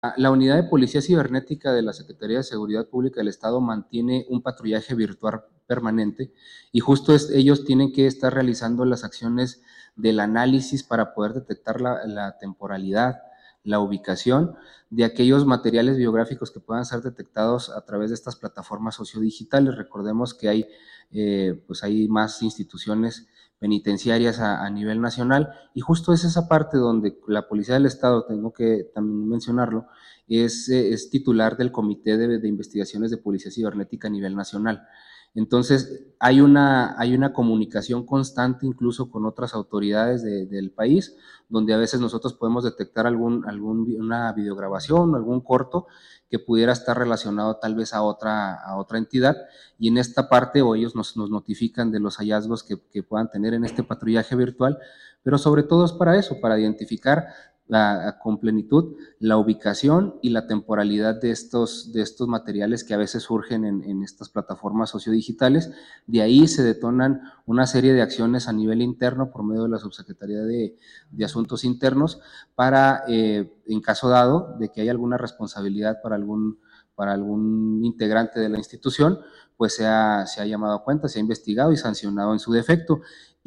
AUDIO: LUIS AGUIRRE, JEFE DE ESTADO MAYOR DE LA SECRETARÍA DE SEGURIDAD PÚBLICA (SSPE) 1